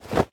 equip_leather1.ogg